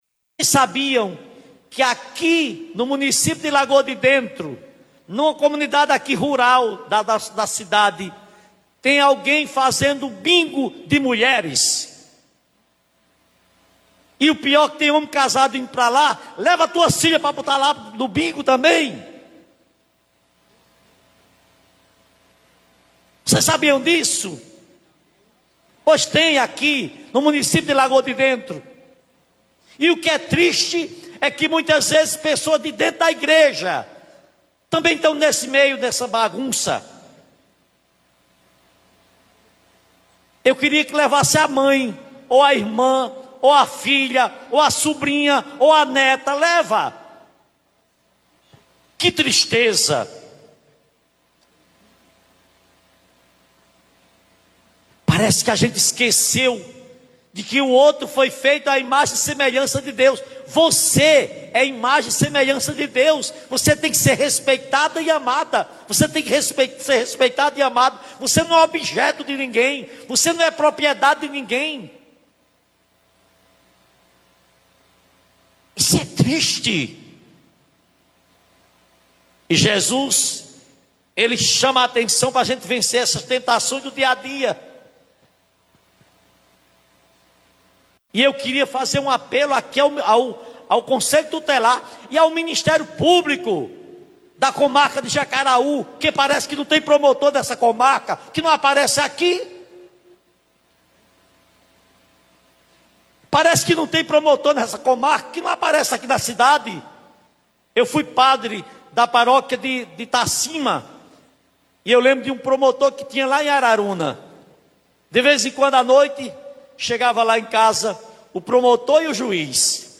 A denúncia foi transmitida ao vivo pelo canal da paróquia no YouTube.